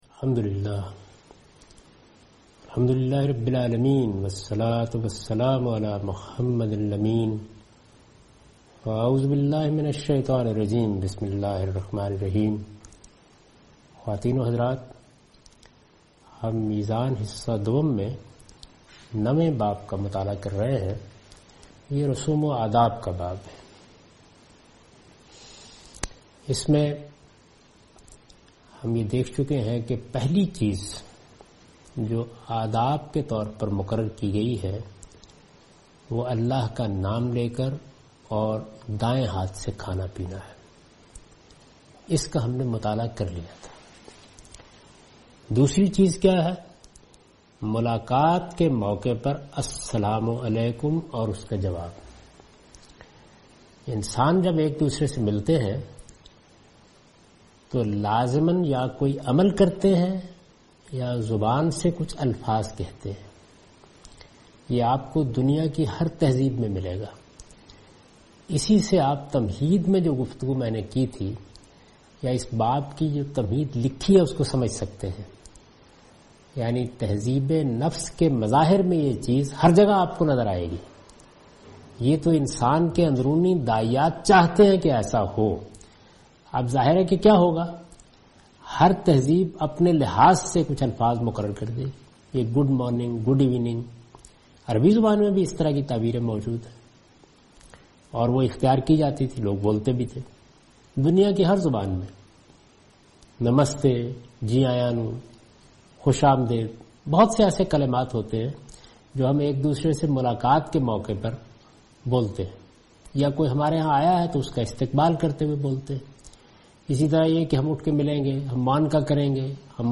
A comprehensive course taught by Javed Ahmed Ghamidi on his book Meezan. In this lecture he will discuss Islamic customs and etiquette. He explains in great detail the place of customs and etiquette in Islamic Shari'ah.